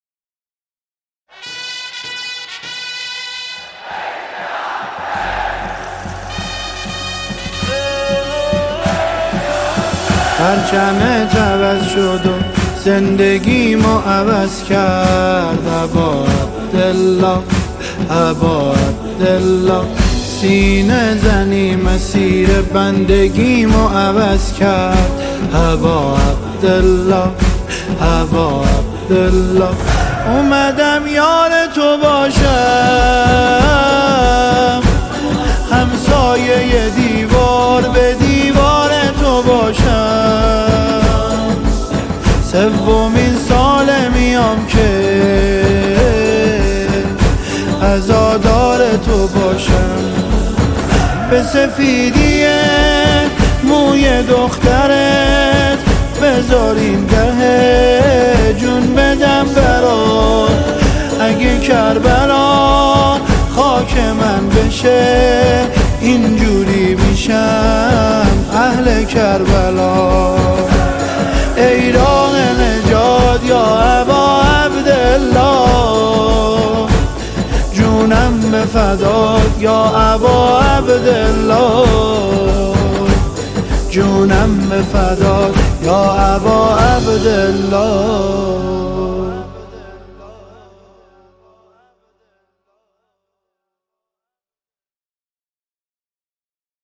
مداحی احساسی استودیویی